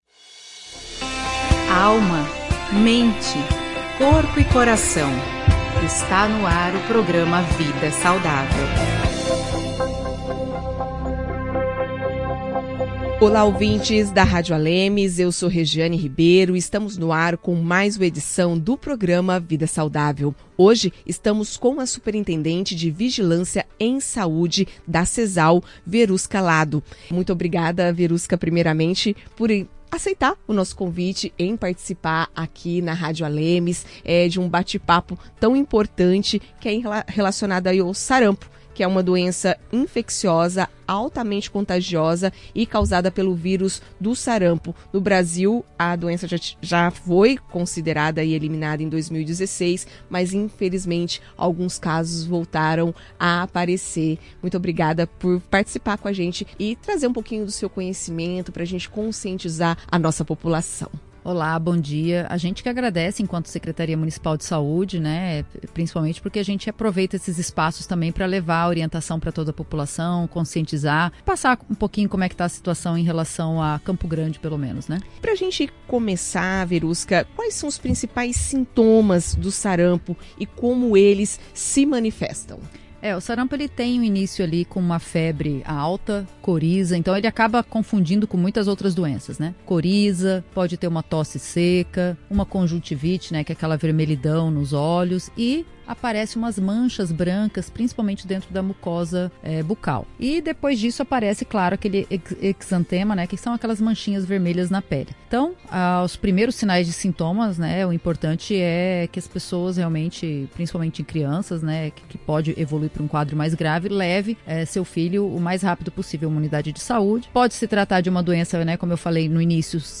O sarampo, doença infecciosa altamente contagiosa, é o tema desta edição do programa Vida Saudável da Rádio ALEMS (105,5 FM).